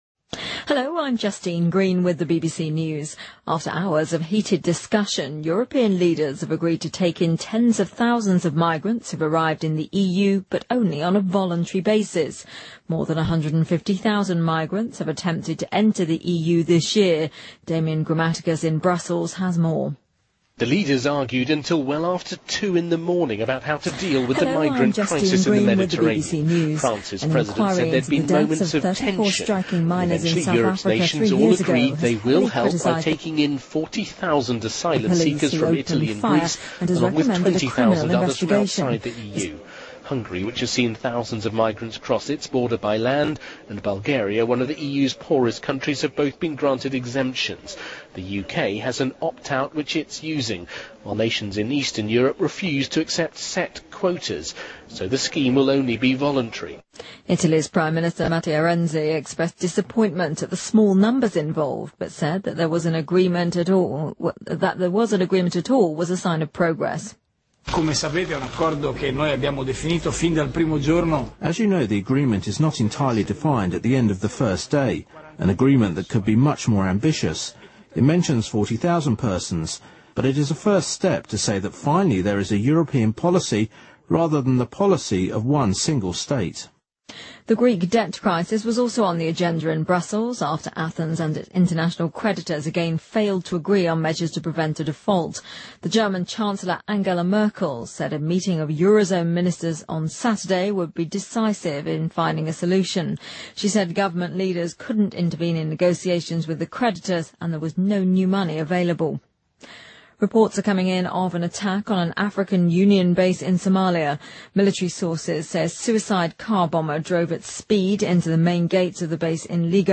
BBC news,多名英国公民在突尼斯海滩枪击案中遇难